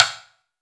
Index of /90_sSampleCDs/AKAI S6000 CD-ROM - Volume 5/Cuba2/TIMBALES_2
F-TIMB CRM-S.WAV